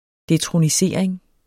Udtale [ detʁoniˈseɐ̯ˀeŋ ]